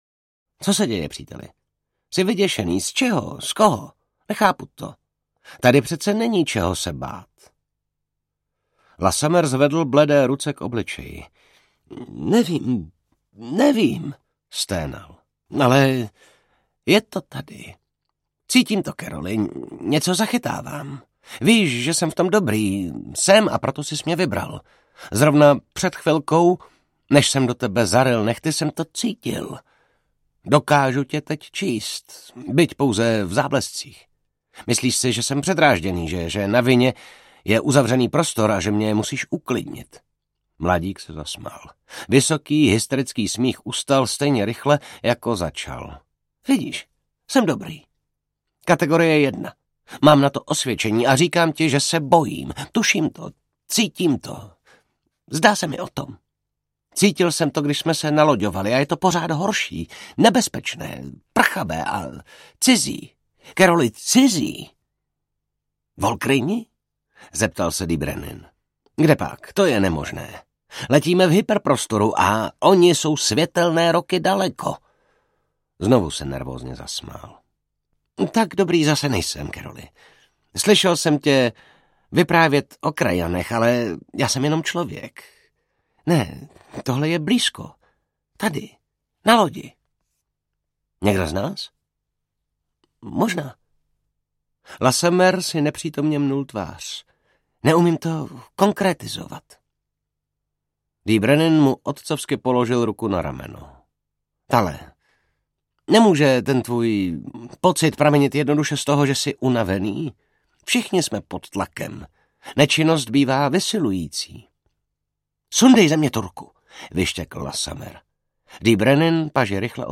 Hörbuch
MP3 Audiobook,